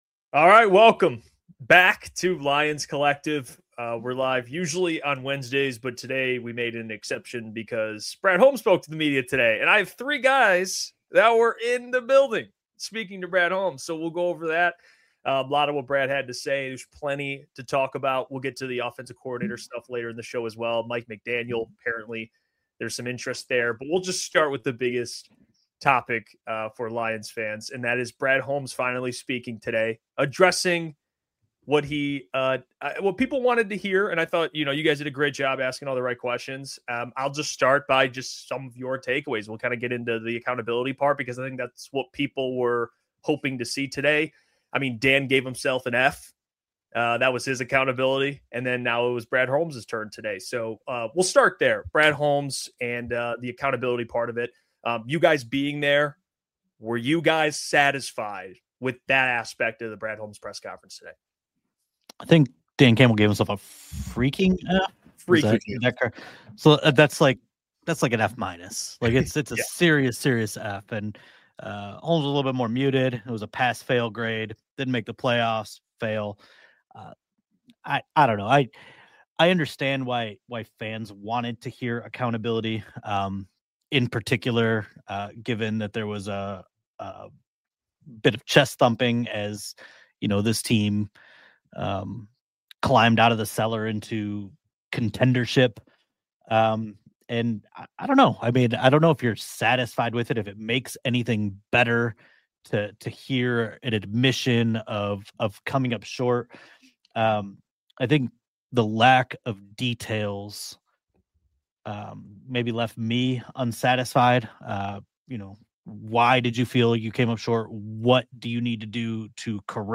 Make sure to subscribe to Lions Collective for new roundtable episodes every week, live, on Wednesday at 6 pm ET You can also hear every episode of Lions Collective wherever you get your podcasts!